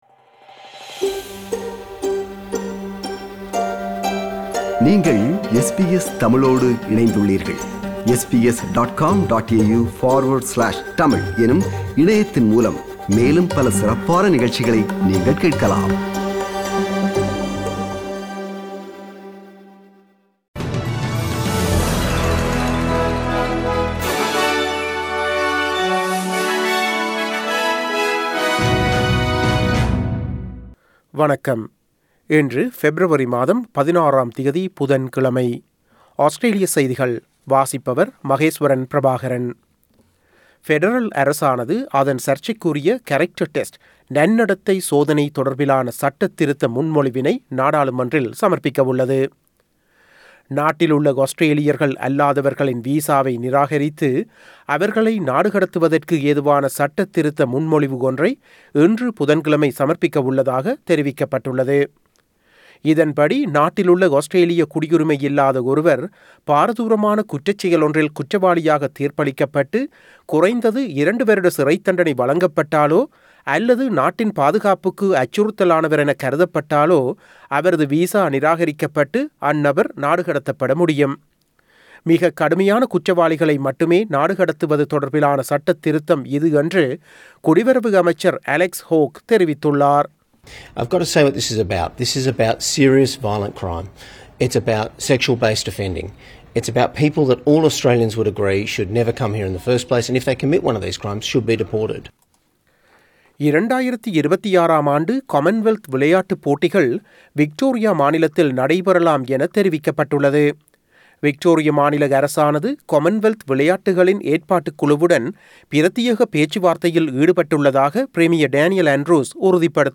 Australian news bulletin for Wednesday 16 February 2022.